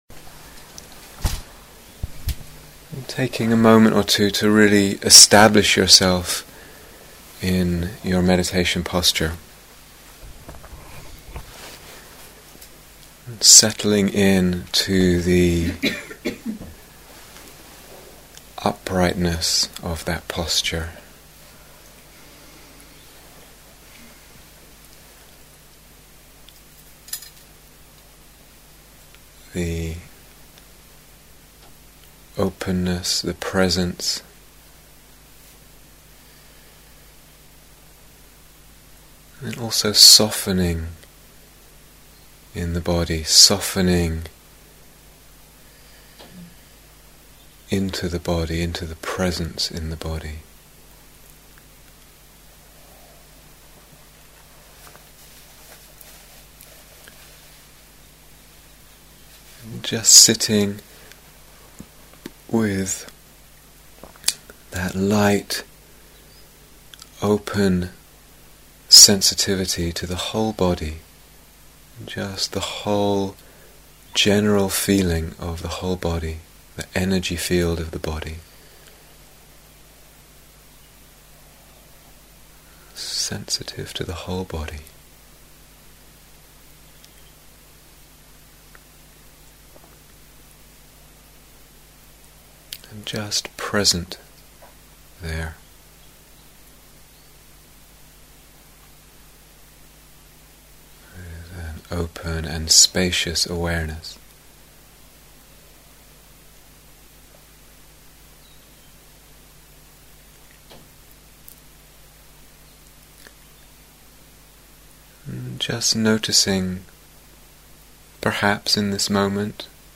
Guided Meditation - Directing Love Towards Dharmas